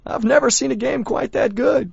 gutterball-3/Gutterball 3/Commentators/Bill/b_neverseensogood.wav at 0b195a0fc1bc0b06a64cabb10472d4088a39178a